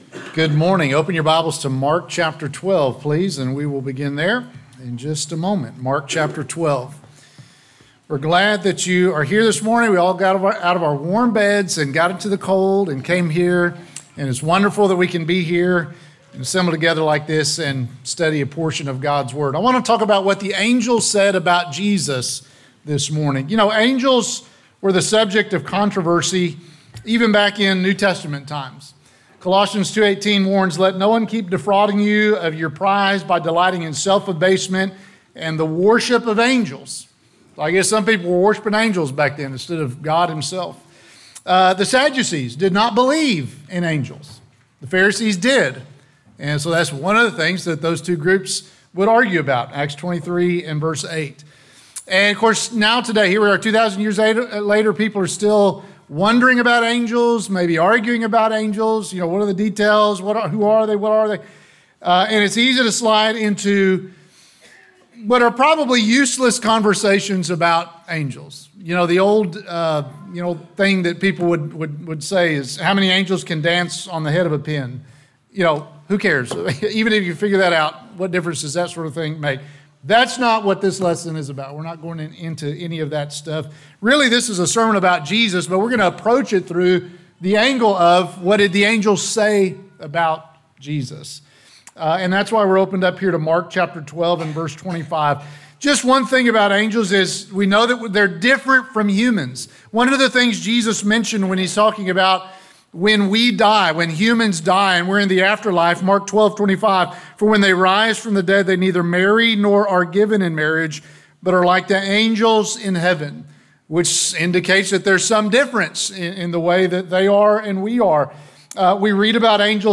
Sermons - Benchley church of Christ